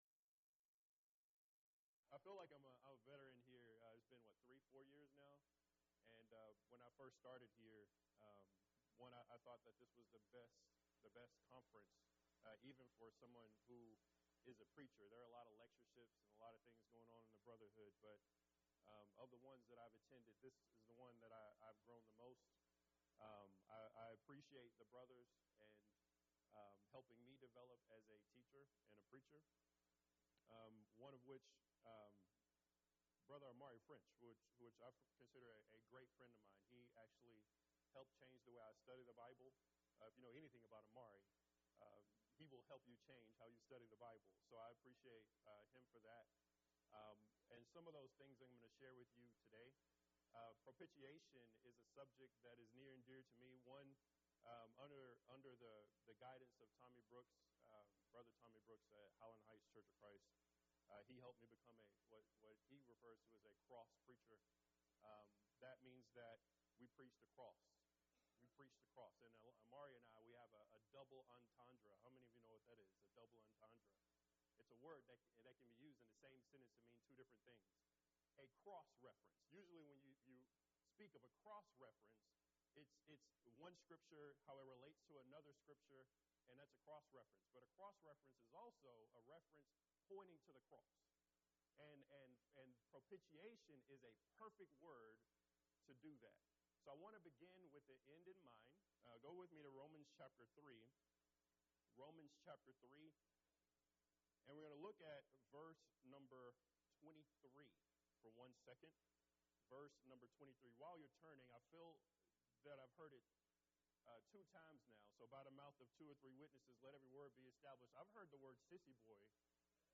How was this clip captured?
Event: 4th Annual Men's Development Conference